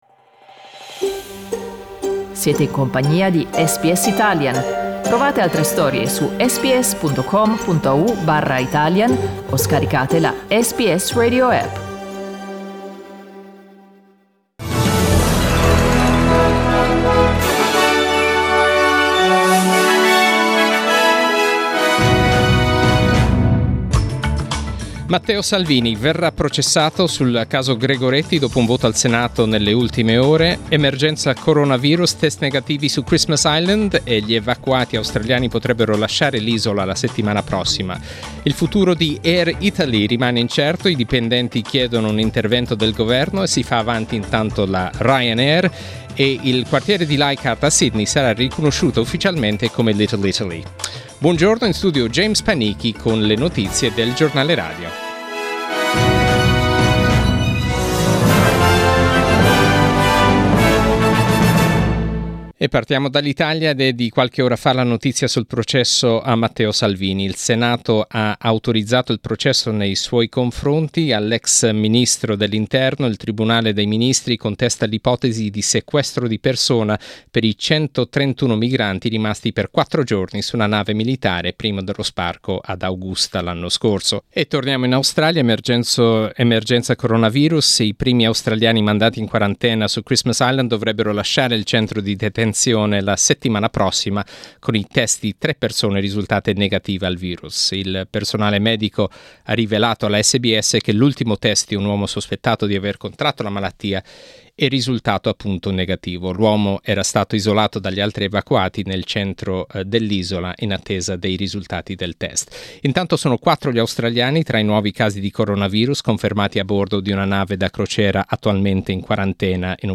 Our news bulletin in Italian